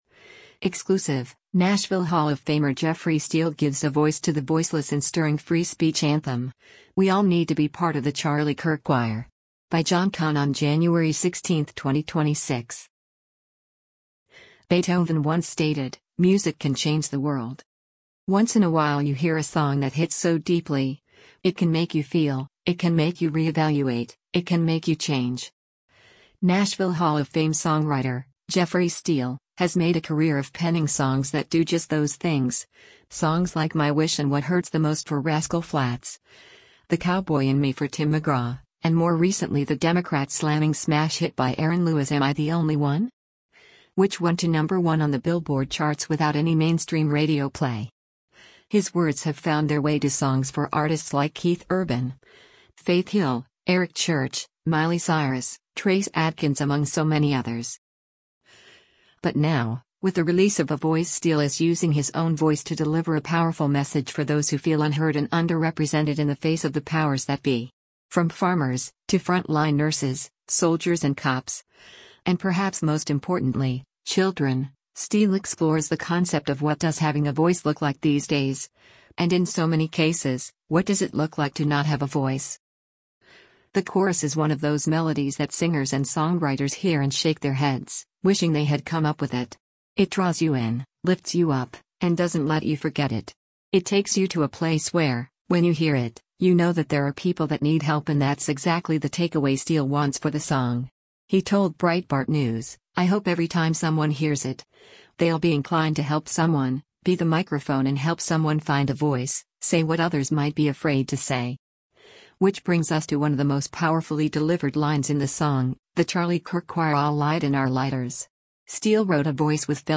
powerhouse performance